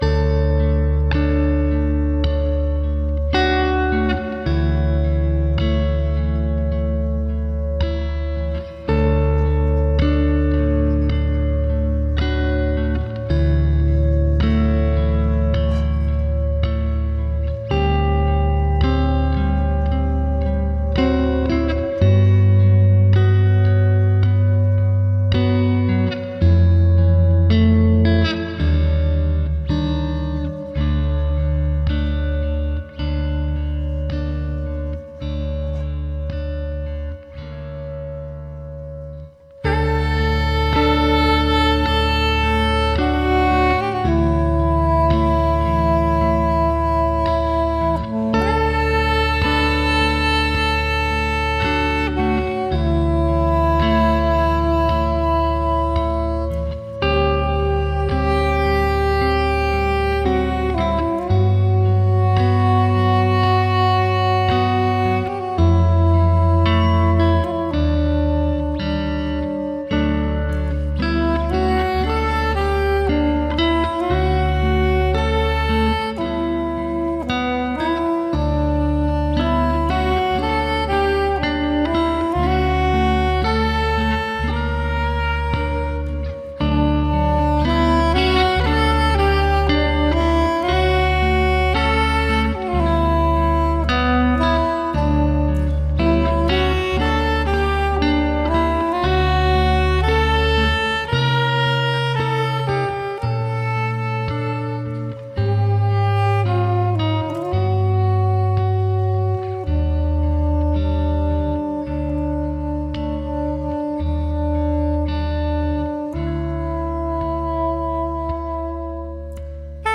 sax soprano
Sono sei minuti di dolcezza.